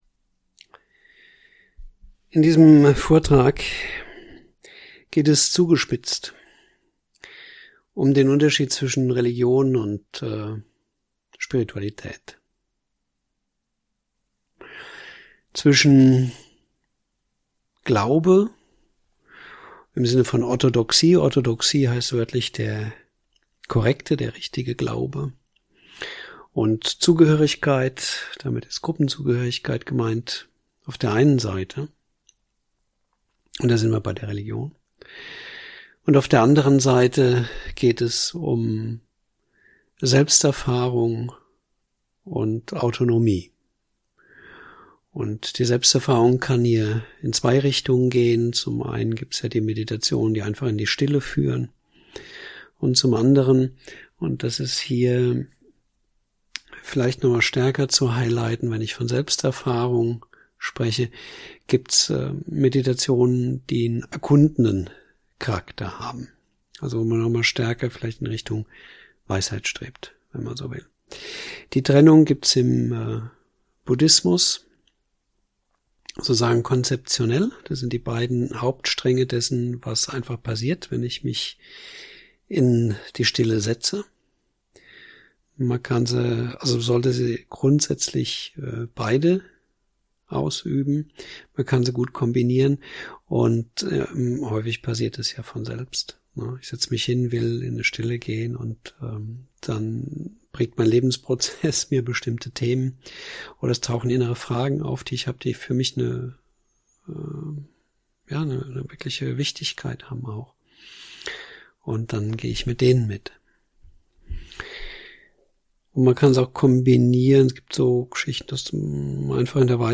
Dieser Vortrag in drei Teilen erläutert umfassend und detailliert die wesentlichen Unterschiede zwischen Religion und Spiritualität.